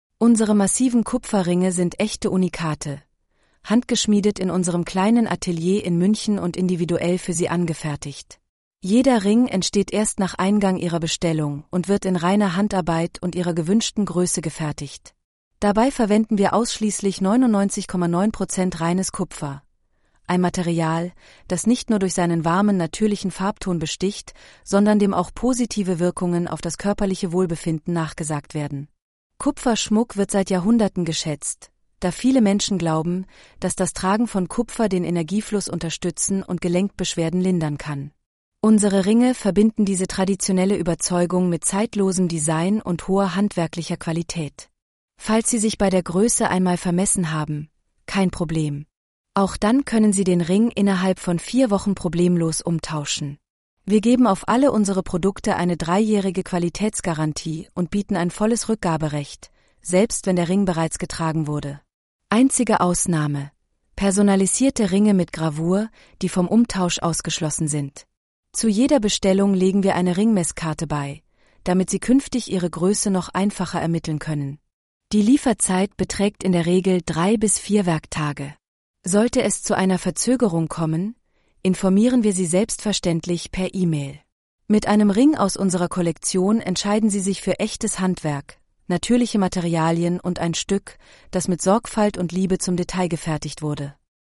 Ring-ttsreader.mp3